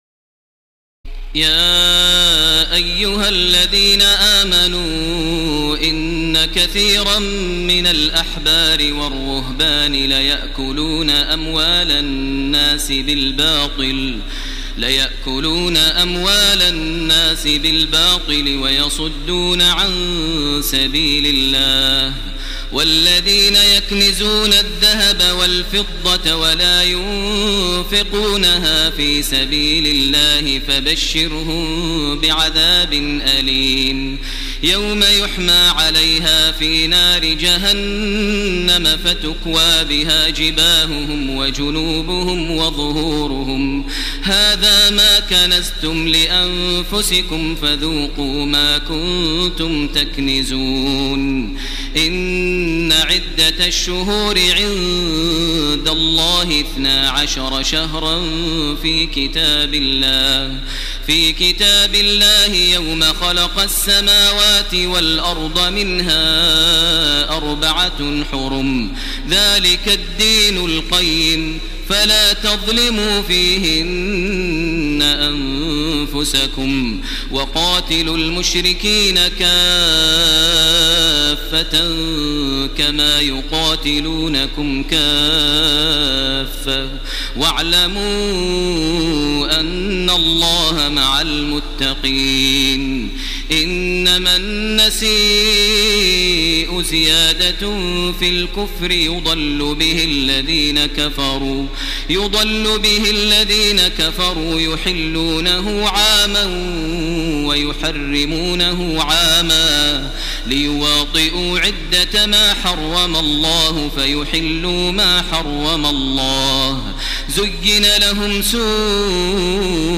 تراويح الليلة العاشرة رمضان 1430هـ من سورة التوبة (34-93) Taraweeh 10 st night Ramadan 1430H from Surah At-Tawba > تراويح الحرم المكي عام 1430 🕋 > التراويح - تلاوات الحرمين